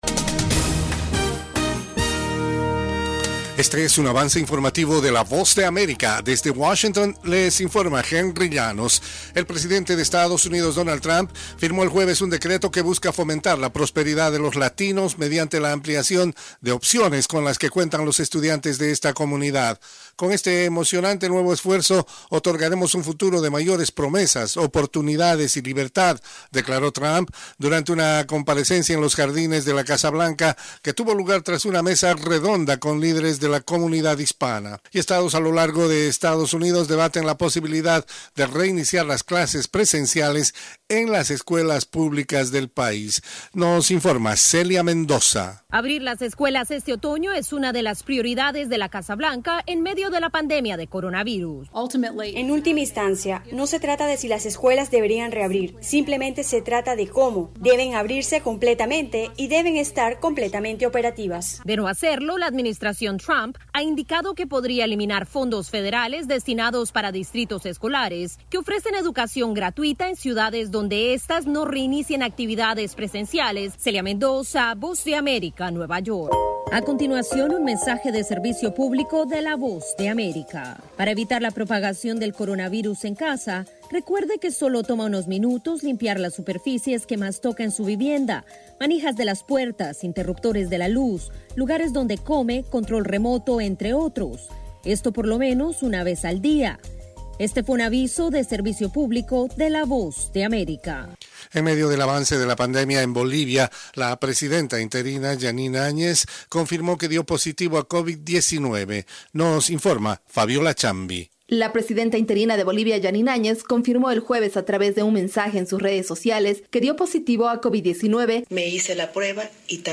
Cápsula informativa de tres minutos con el acontecer noticioso de Estados Unidoa y el mundo.